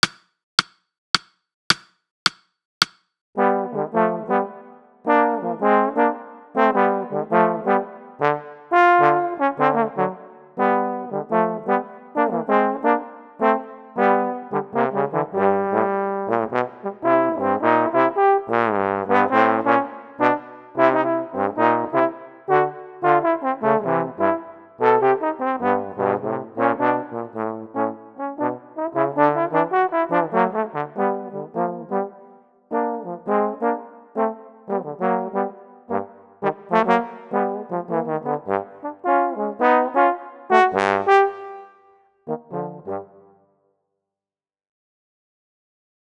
2 Posaunen